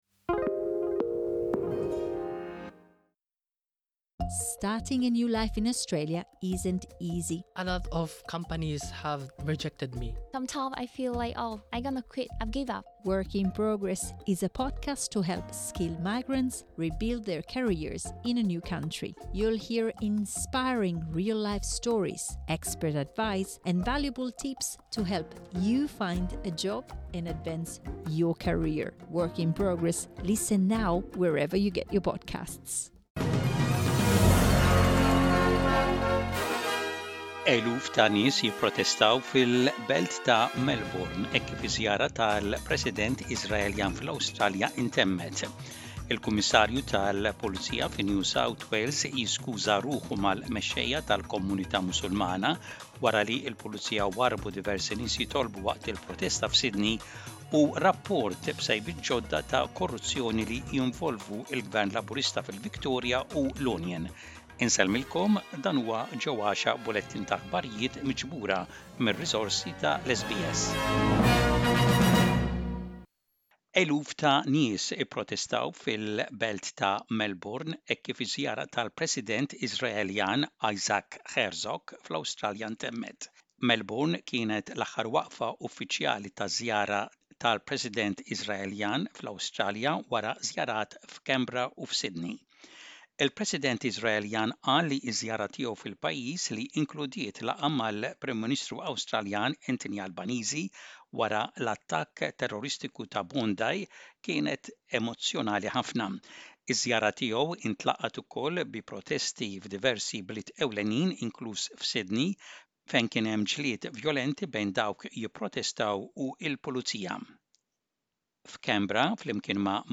SBS Maltese News - Image SBS Maltese